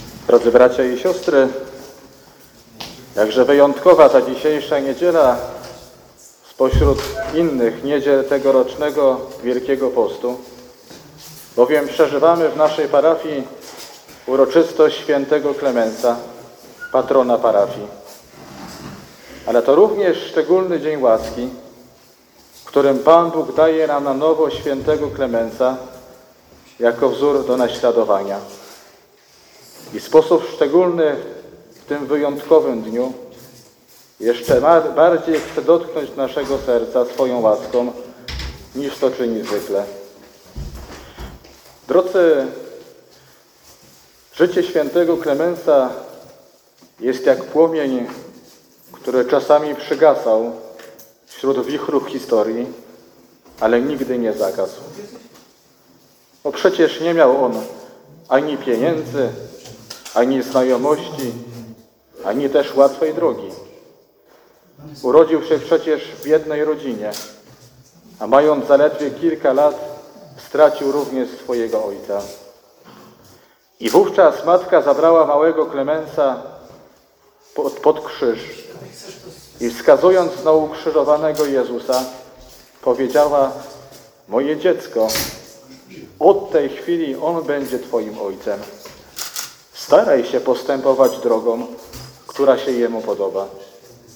fragmenty homilii audio: